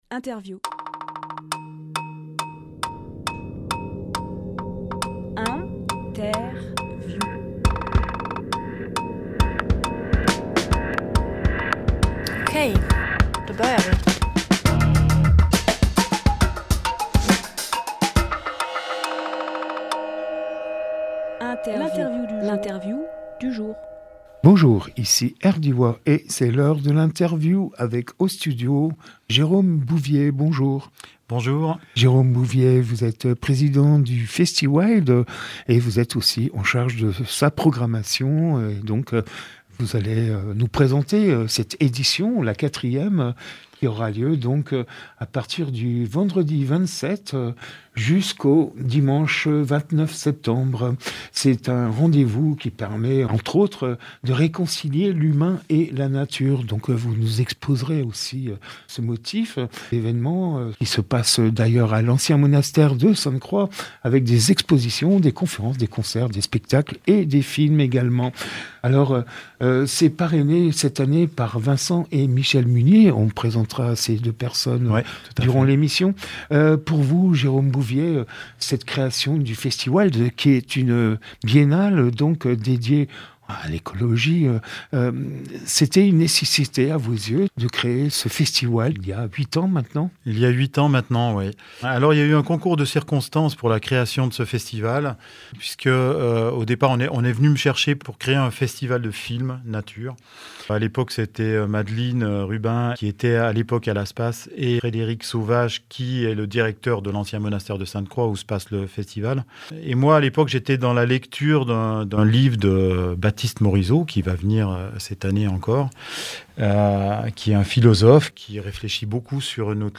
INTERVIEW RDWA